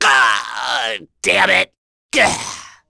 Ezekiel-Vox_Dead.wav